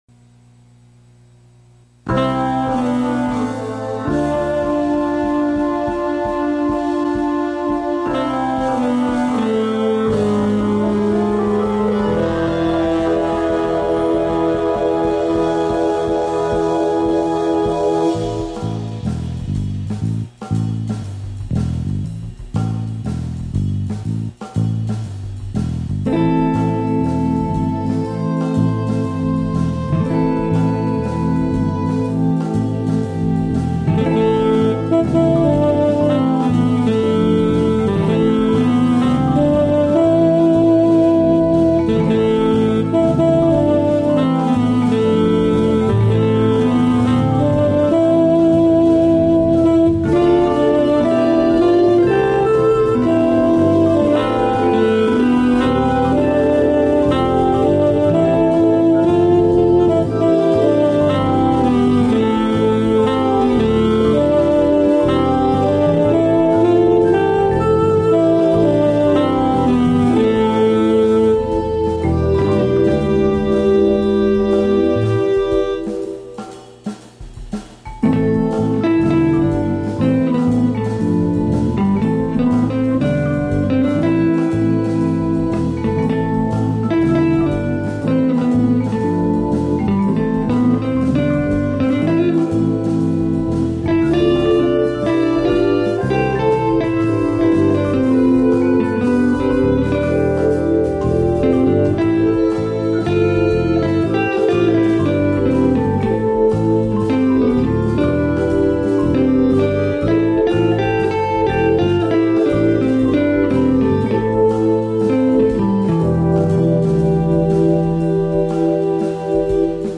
In a holiday Aeolian mode, here's a 2012 instrumental jazz arrangment of my own arrangement of the English Chritsmas Carol